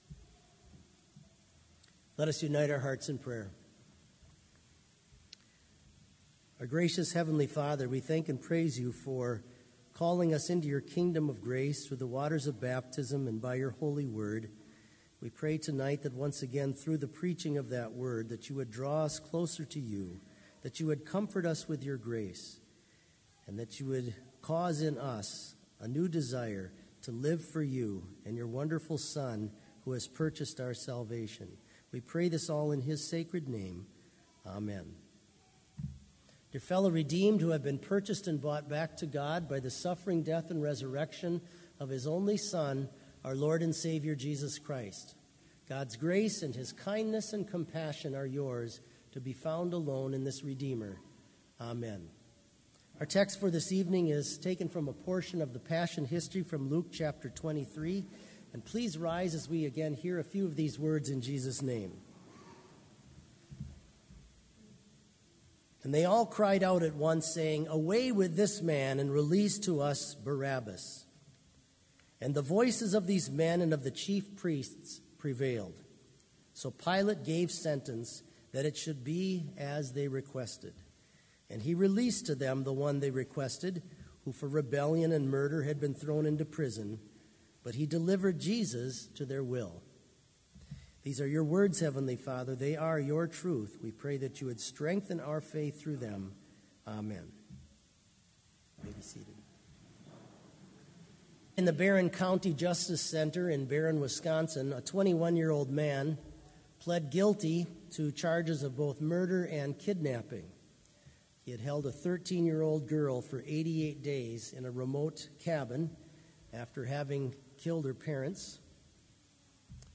Complete service audio for Lent - March 27, 2019